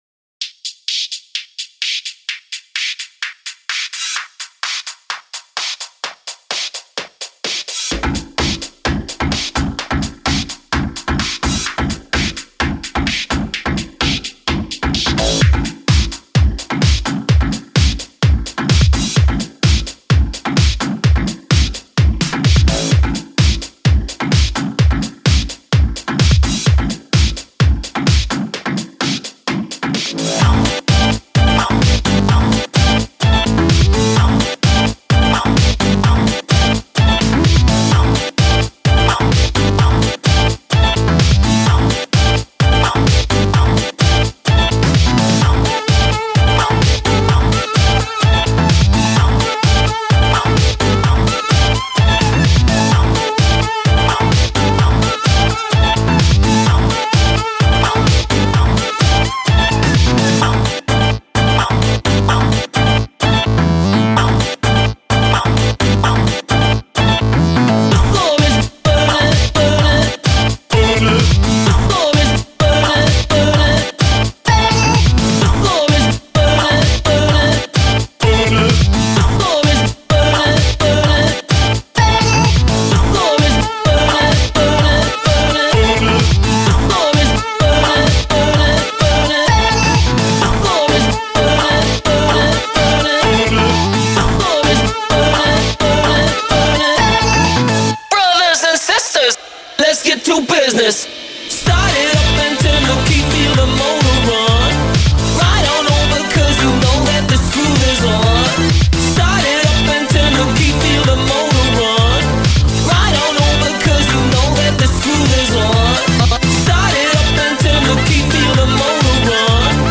This particularly track is a hard disco funk take